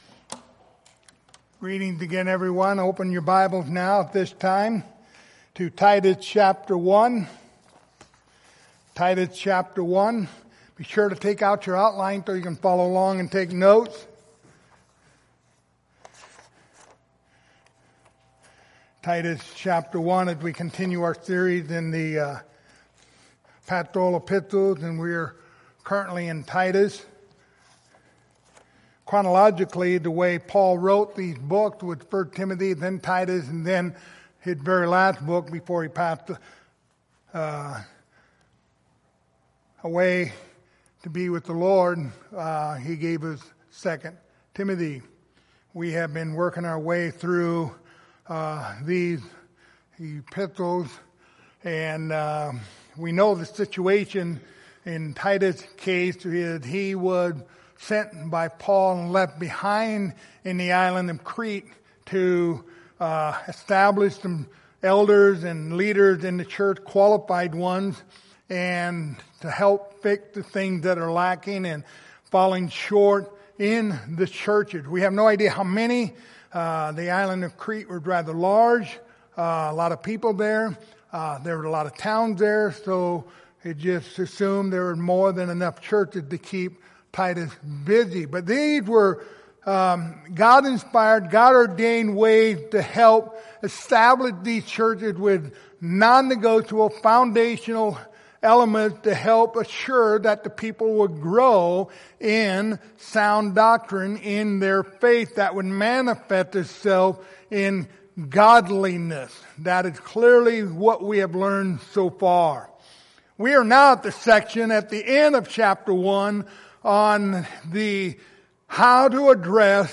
Passage: Titus 1:14-16 Service Type: Sunday Morning